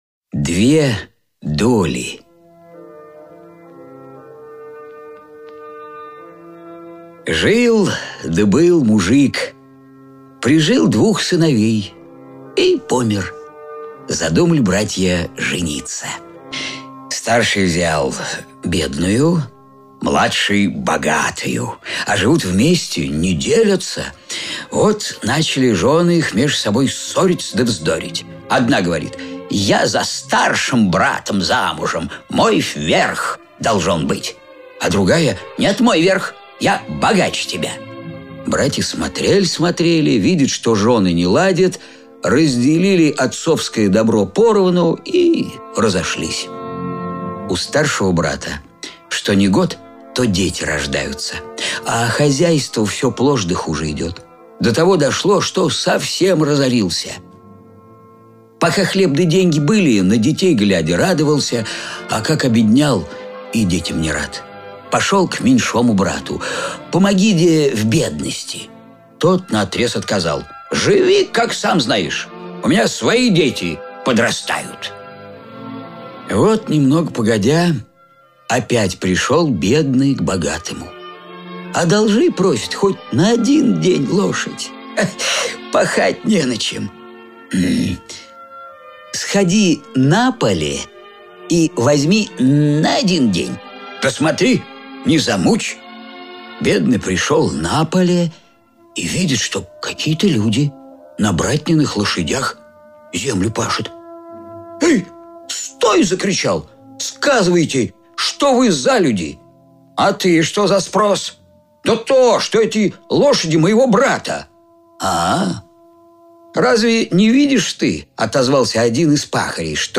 Аудиокнига Золотое веретено. Старинные сказки и колыбельные | Библиотека аудиокниг